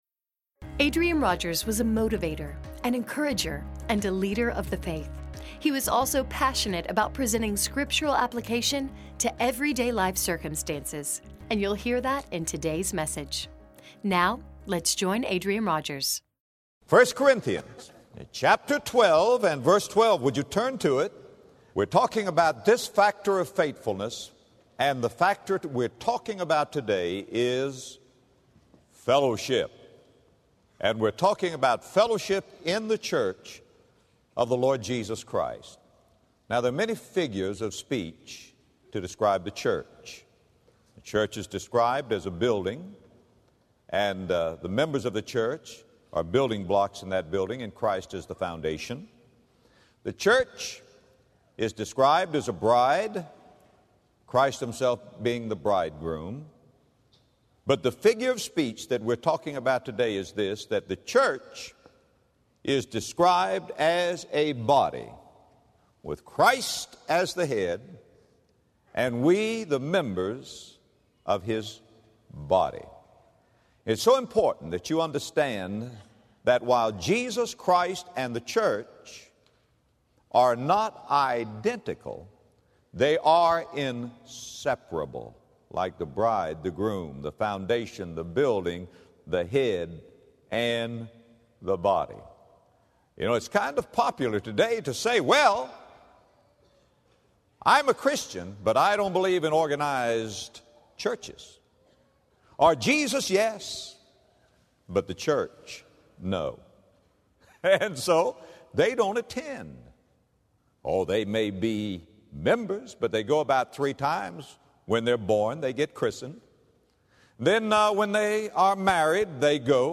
Faithful in Fellowship Podcast with Adrian Rogers